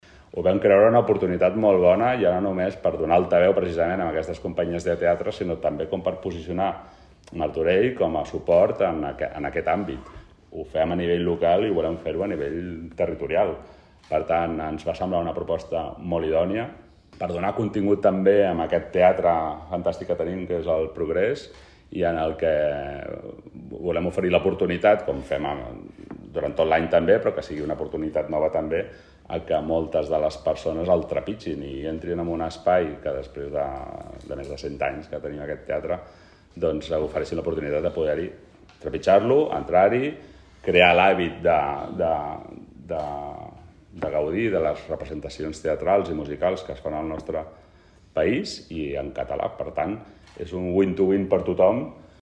Roda de Premsa presentació 14è Tast d'Espectacles Familiars
Sergi Corral, regidor de Cultura